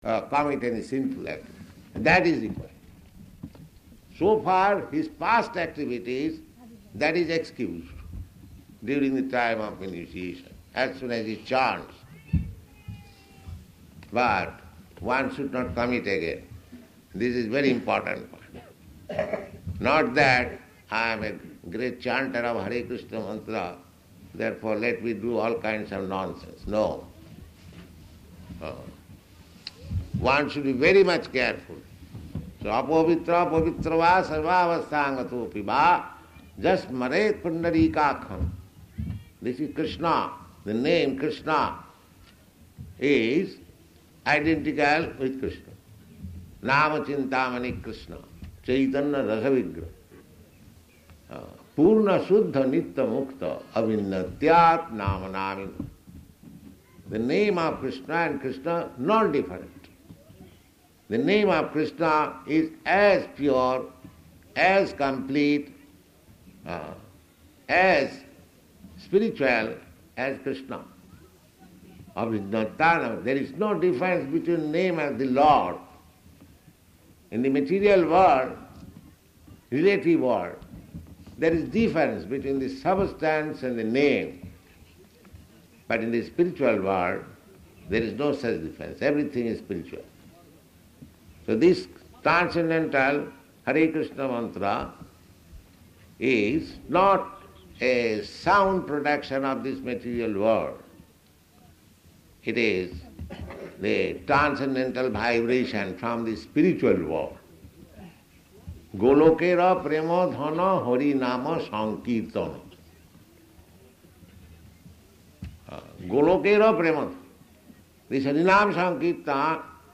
Gaura-Pūrṇimā --:-- --:-- Type: Lectures and Addresses Dated: February 29th 1972 Location: Māyāpur Audio file: 720229GP.MAY.mp3 Prabhupāda: ...commit any sinful activities, that is required.